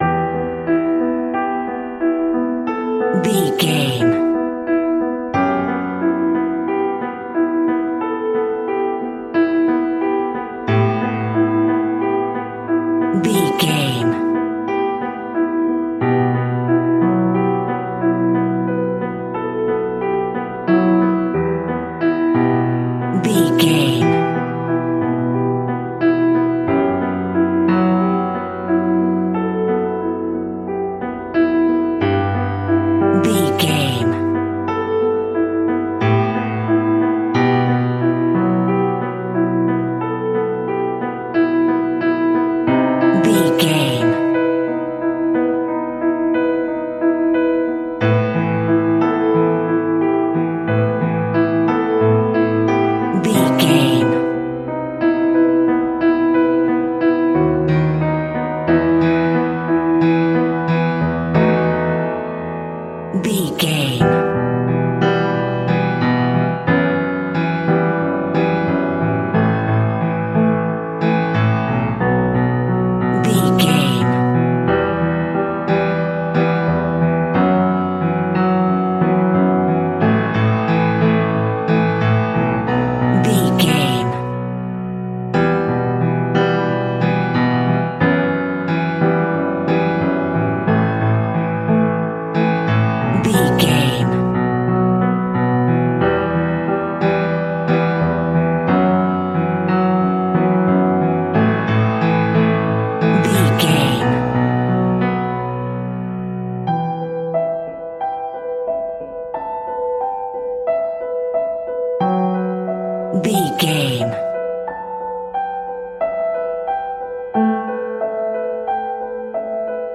Scary Piano Music Cue.
Aeolian/Minor
tension
ominous
haunting
eerie